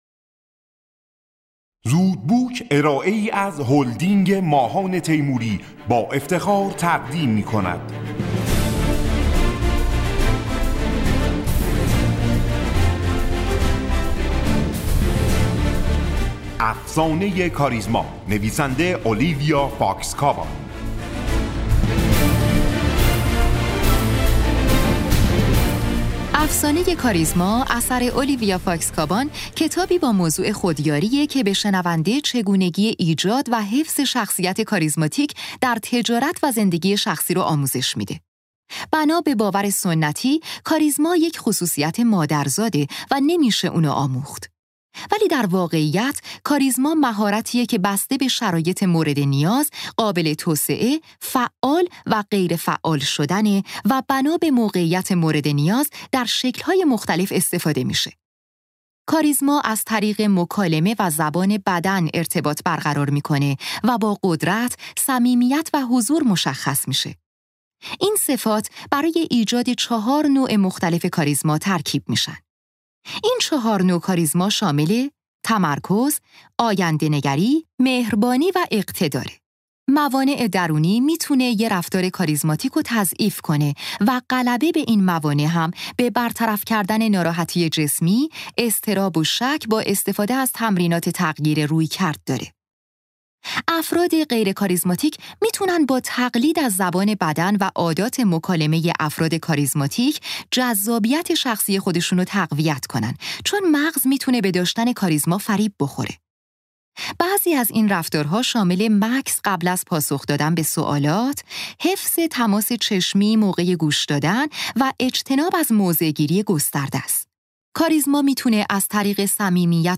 خلاصه کتاب صوتی افسانه کاریزما